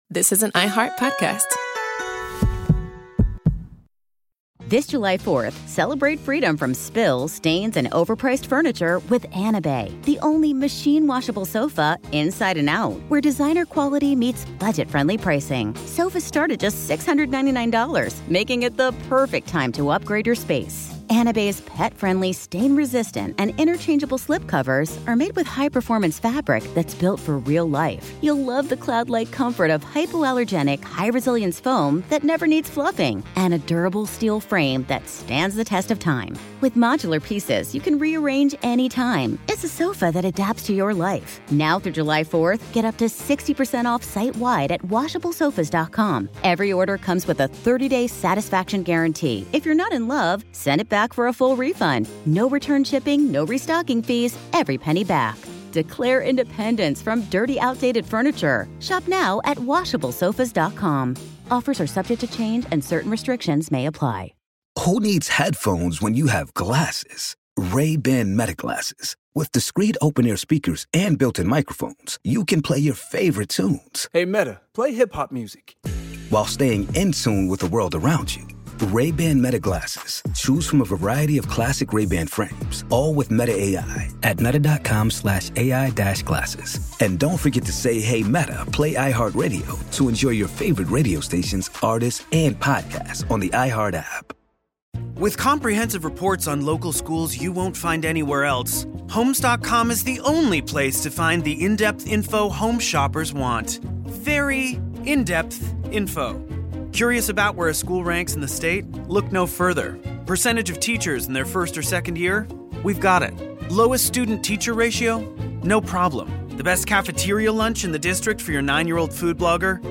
On this episode of Our American Stories, On July 4, 1986, moments before the largest fireworks display in American history, President Ronald Reagan gave a rousing speech from the deck of theÂ USS John F. KennedyÂ in New York Harbor. Standing beneath the Statue of Liberty, he reminded the country and the world what freedom really means.